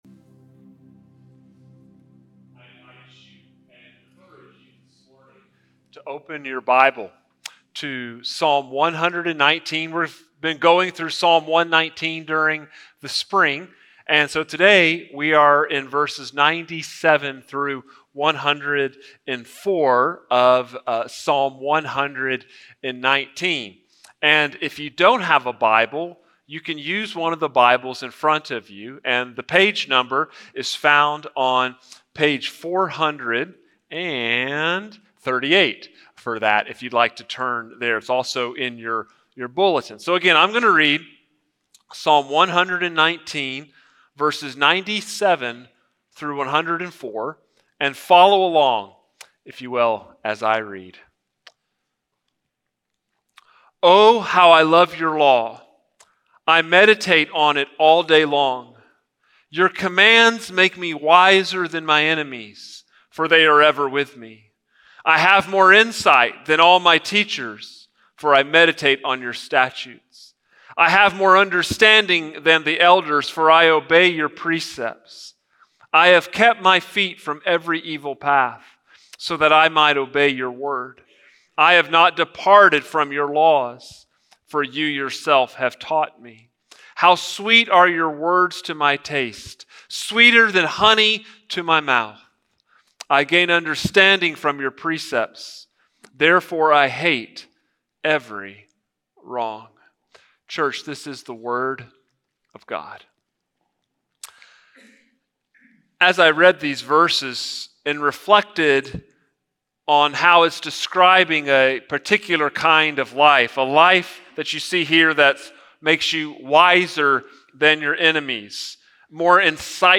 Sermons | First Baptist Church, Brenham, Texas